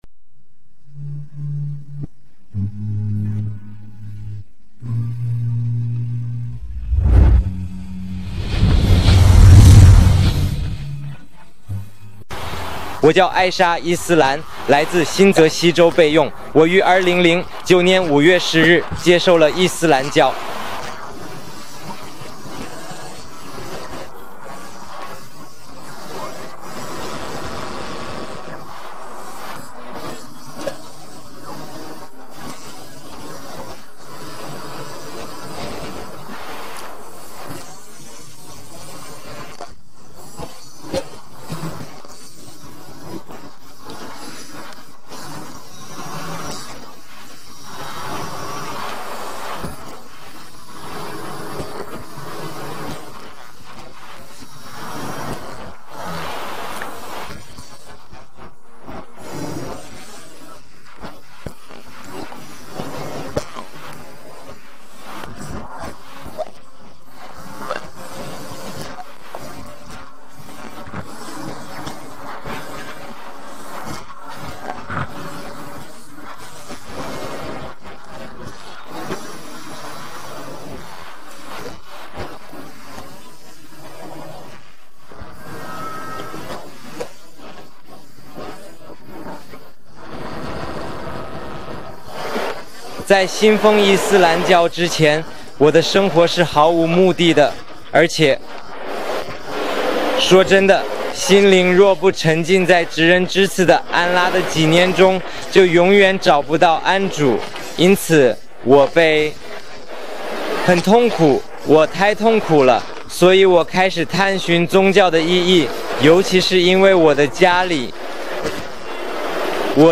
视频 新穆斯林故事 男性